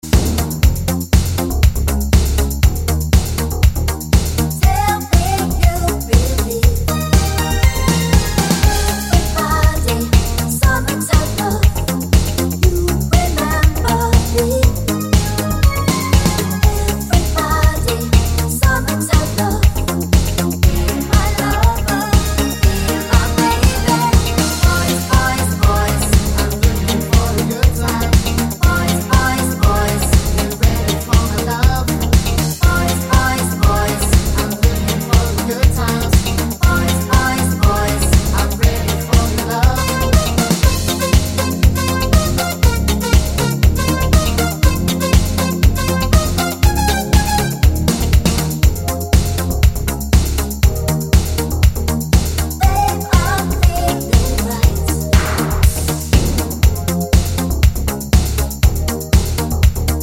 No Male Rap Pop (1980s) 3:59 Buy £1.50